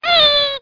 1 channel
toysqk0g.mp3